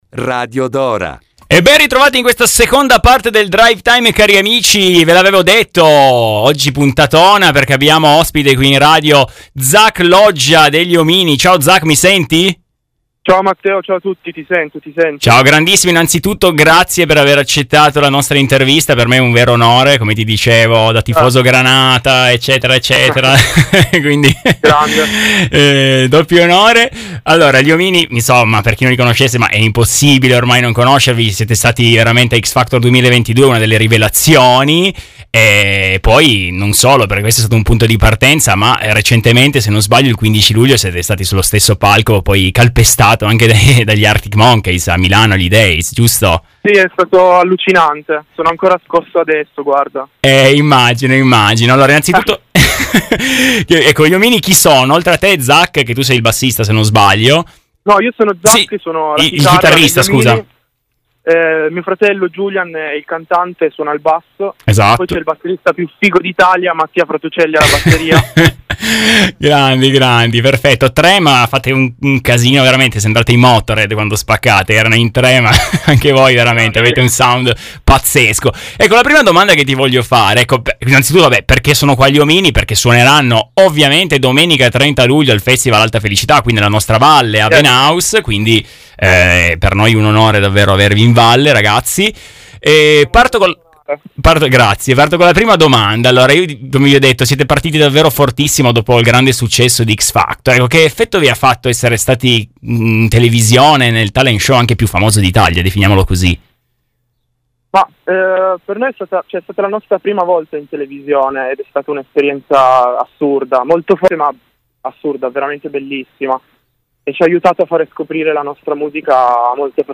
Festival Alta Felicità 2023 - Intervista